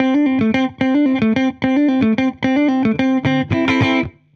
Index of /musicradar/dusty-funk-samples/Guitar/110bpm
DF_70sStrat_110-A.wav